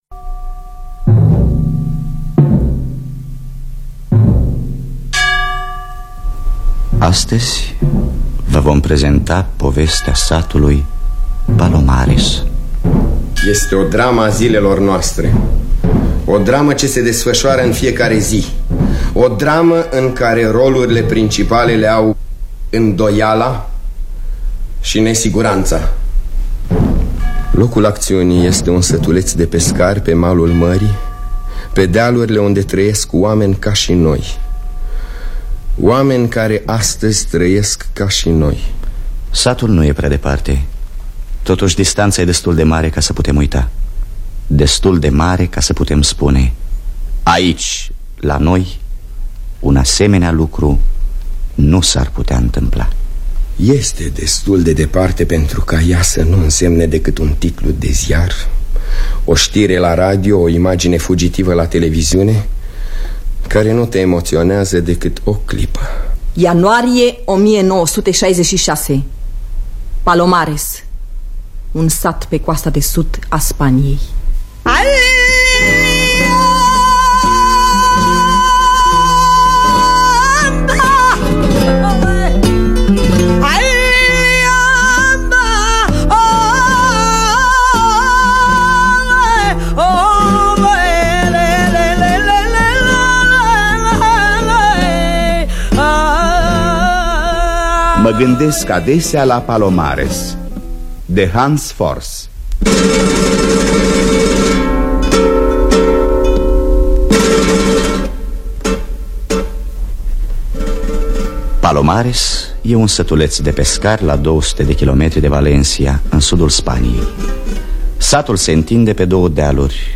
Mă gândesc adesea la Palomares de Hans Alexander Fors – Teatru Radiofonic Online